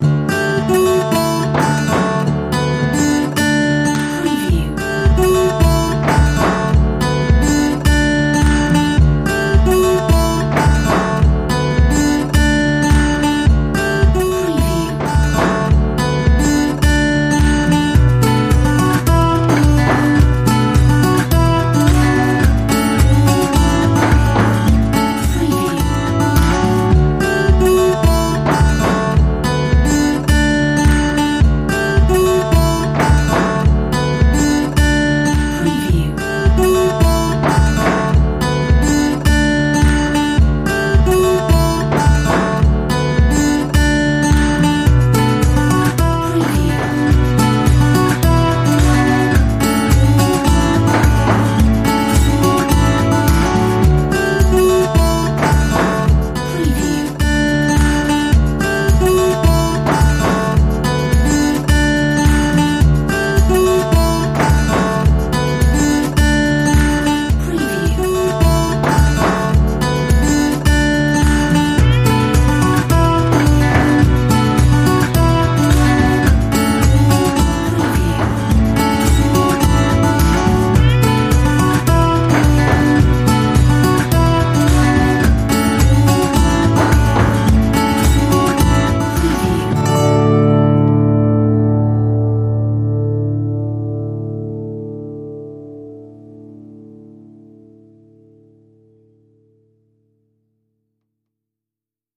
Acoustic goodness